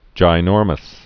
(jī-nôrməs)